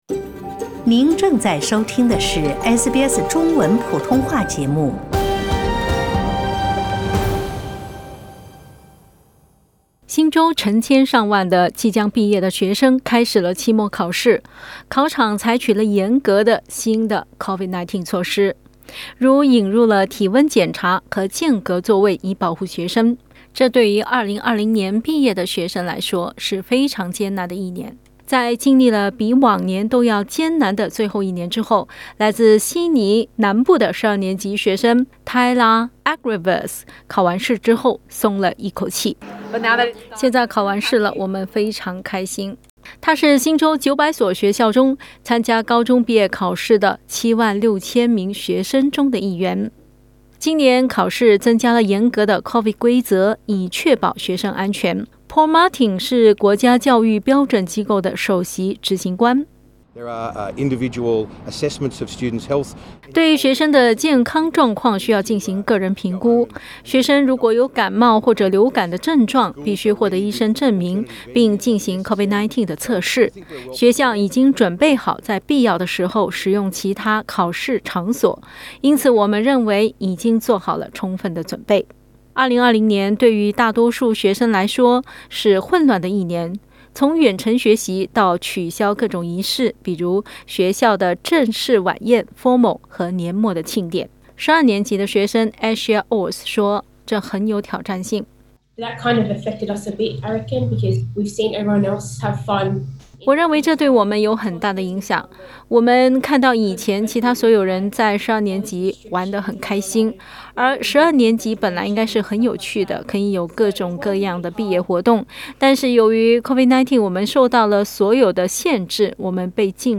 新州成千上万的即将毕业的学生开始了期末考试，考场采取了严格的应对COVID-19的措施。 点击图片收听详细报道。